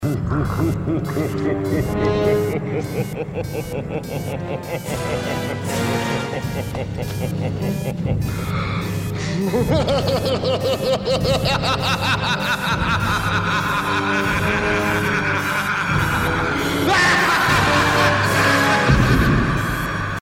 Звуки хищника
На этой странице собраны звуки хищников: рычание, вой, крики и другие устрашающие аудио.
Хищник – жуткий звук смеха